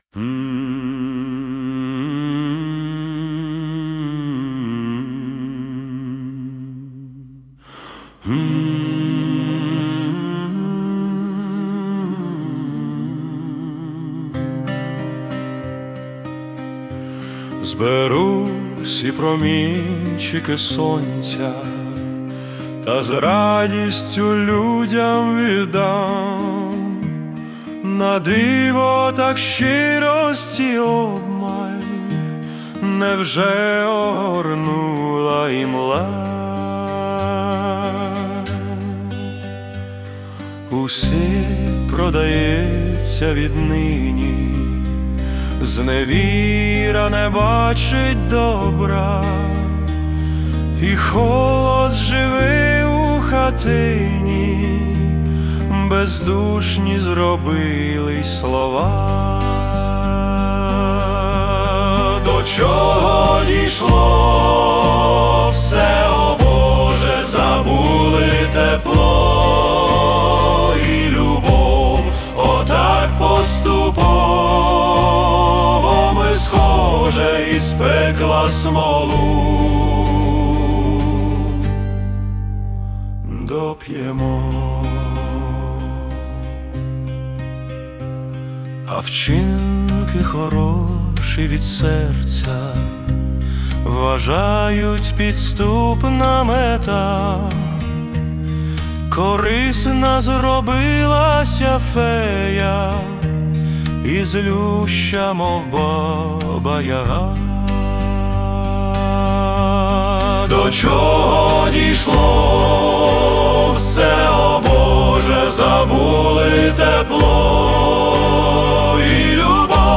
Музична композиція створена за допомогою SUNO AI
СТИЛЬОВІ ЖАНРИ: Ліричний
Приємний чоловічий голос... потім кілька голосів.